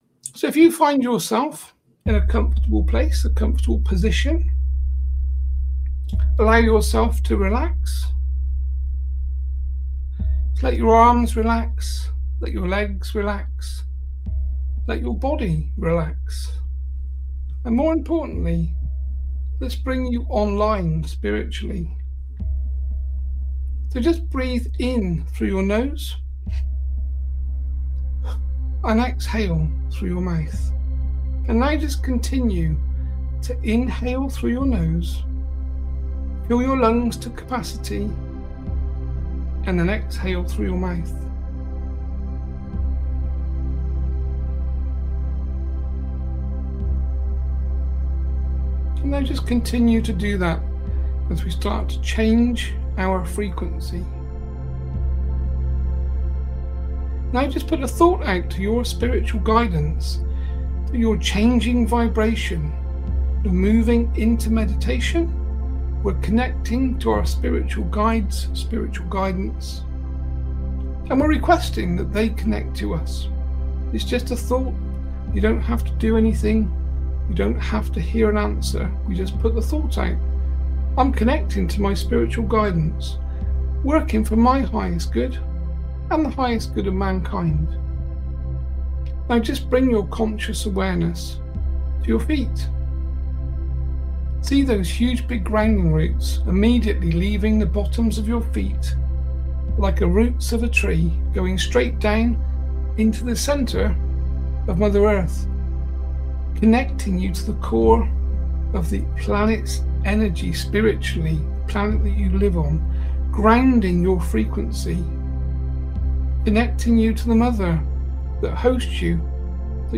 Guided Meditation to Clear Negativity & Step Into Your True Power